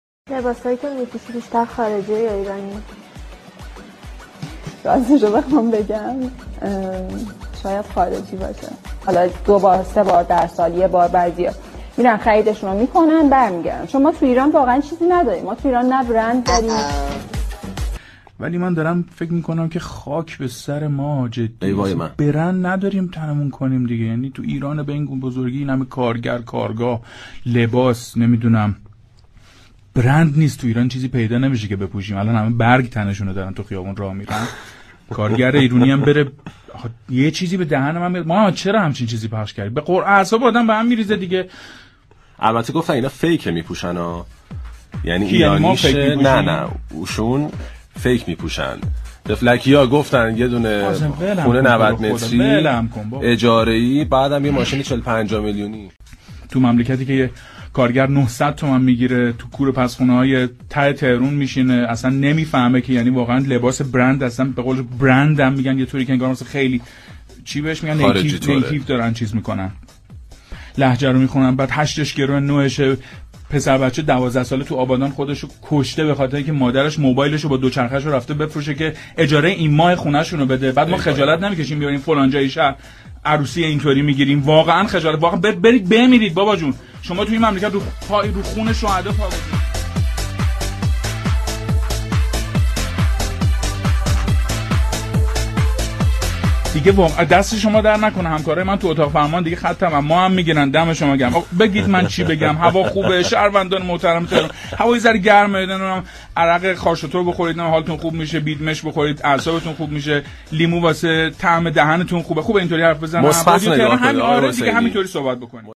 صوت/ عصبانیت مجری رادیو از برندبازی عروس سفیر؛ برید بمیرید!